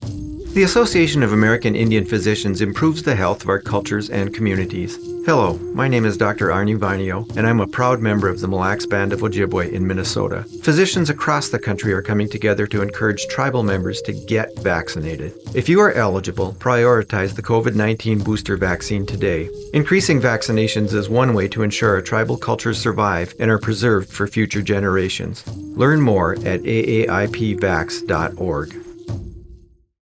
Radio / Televsion PSAs